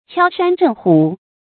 敲山震虎 注音： ㄑㄧㄠ ㄕㄢ ㄓㄣˋ ㄏㄨˇ 讀音讀法： 意思解釋： 見「敲山振虎」。